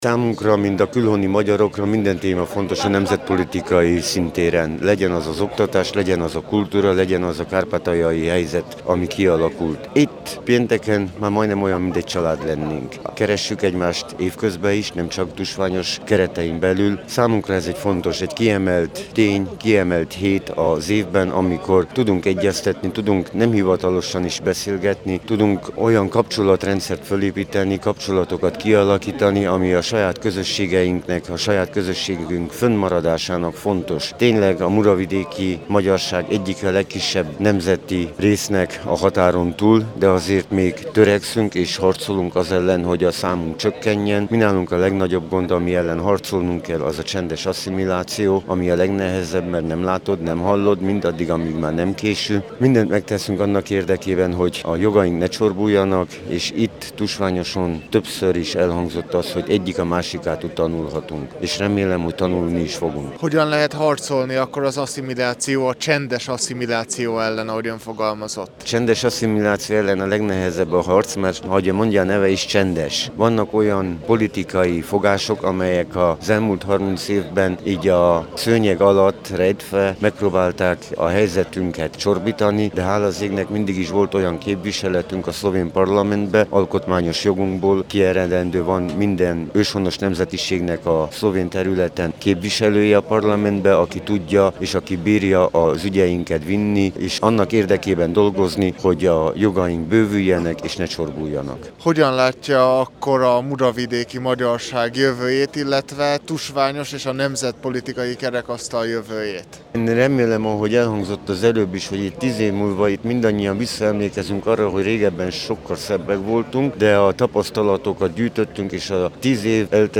A választási eredményekről, az együttműködésről, a célkitűzésekről és a román politikai nyomásról beszélt Tánczos Barna szenátor, volt környezetvédelmi miniszter, aki a kerekasztalbeszélgetés után rádiónknak is nyilatkozott.